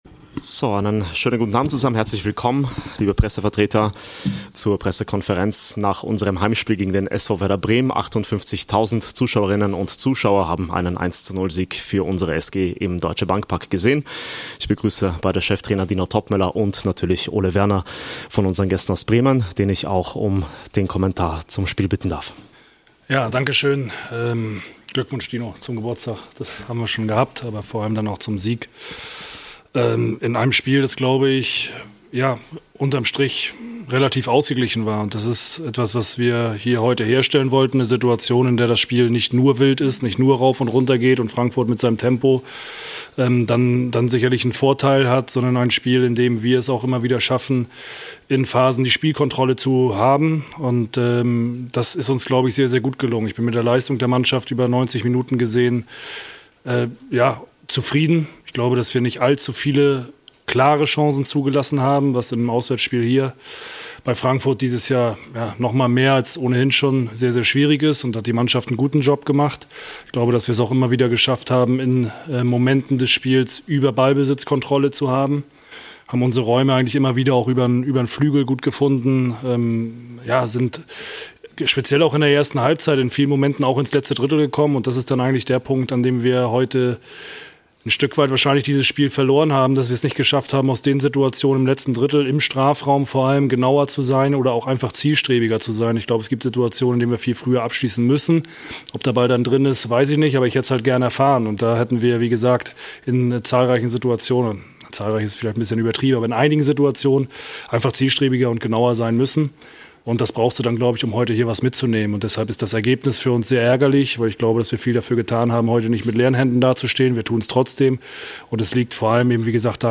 Die Pressekonferenz mit beiden Cheftrainern nach dem Heimspiel gegen Werder Bremen.